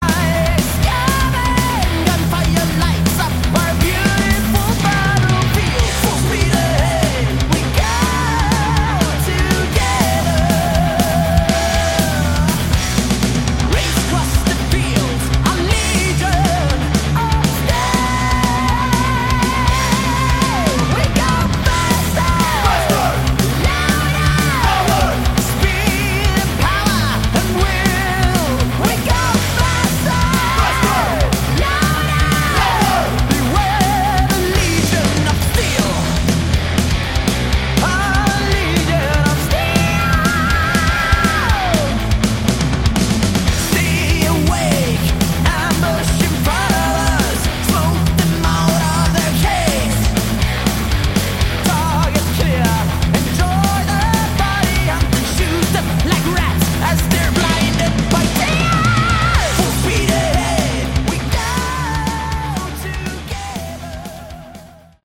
Category: Melodic Metal
vocals
guitars
bass
drums